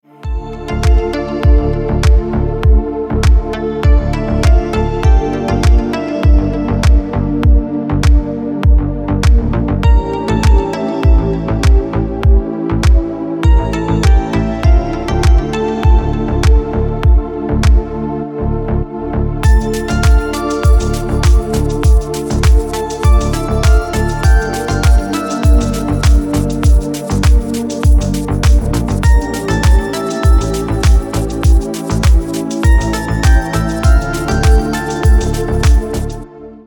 Красивые мелодии на телефон, мелодичные рингтоны Качество